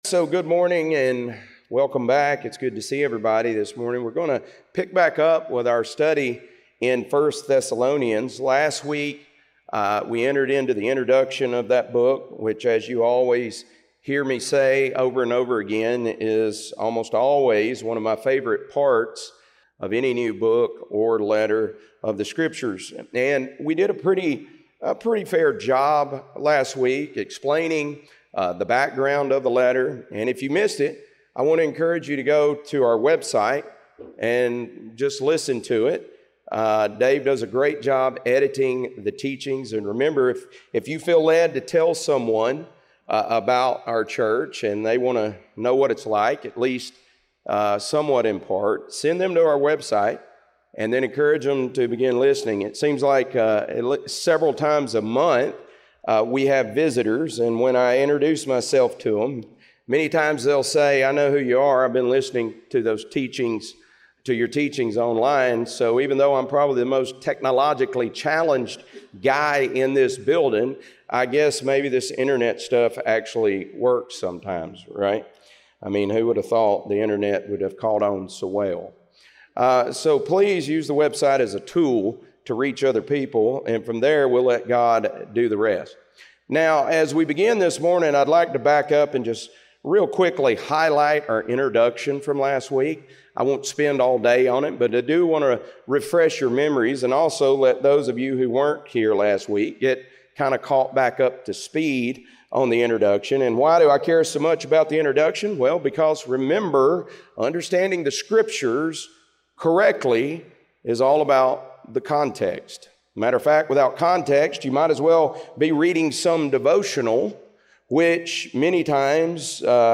1 Thessalonians - Lesson 1B | Verse By Verse Ministry International